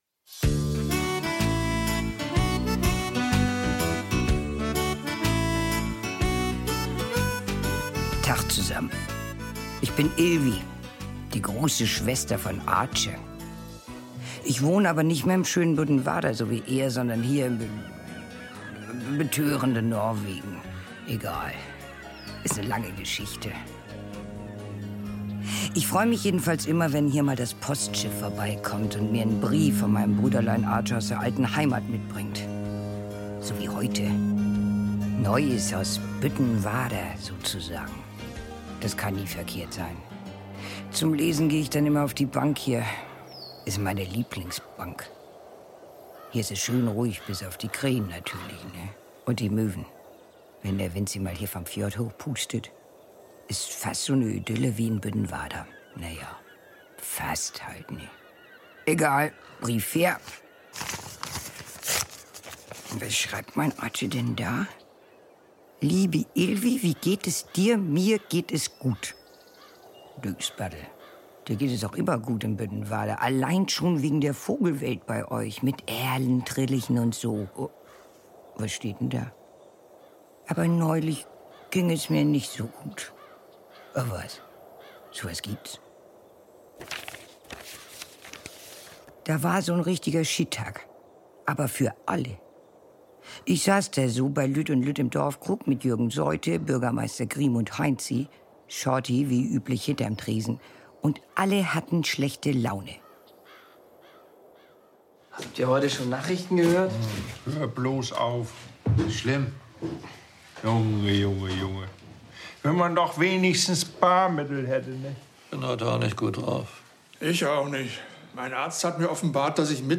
Büttenwarder-Hörspiel: Topptach ~ Neues aus Büttenwarder Podcast